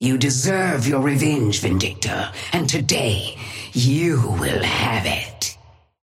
Sapphire Flame voice line - You deserve your revenge, Vindicta, and today you will have it.
Patron_female_ally_hornet_start_03.mp3